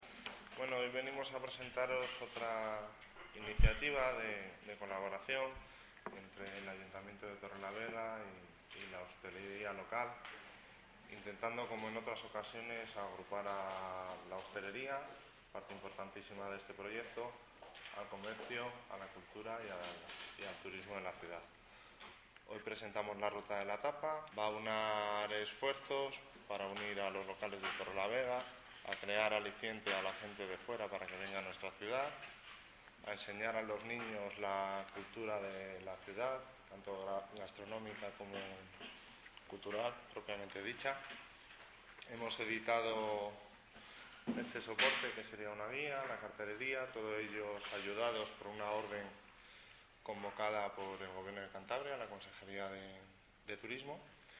Javier L. Estrada, concejal de Empleo, Industria y Comercio